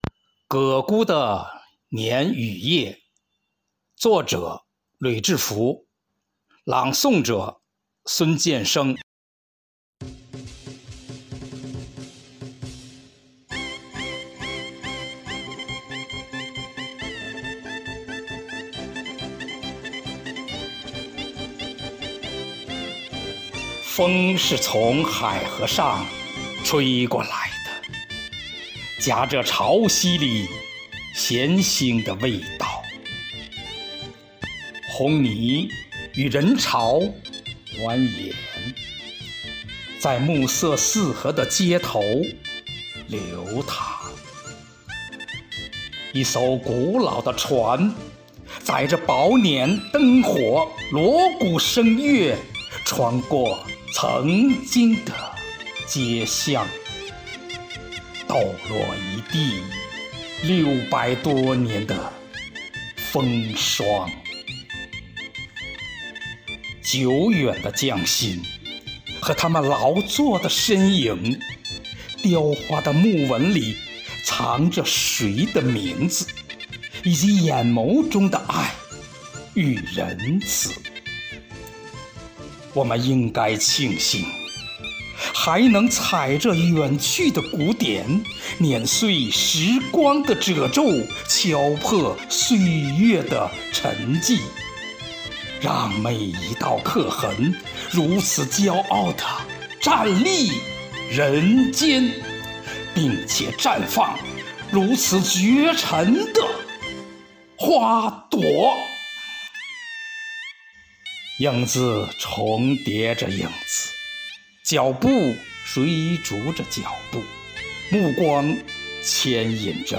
第十届全民创意写作“海河诗人”诗歌创作活动——成人组（十五）